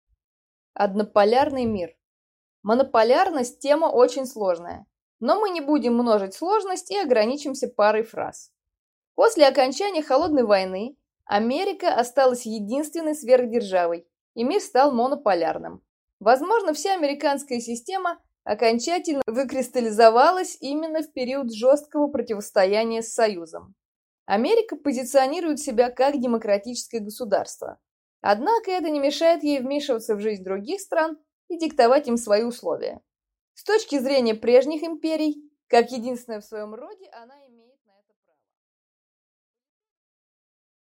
Аудиокнига Монополярный мир | Библиотека аудиокниг